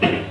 bumper1.wav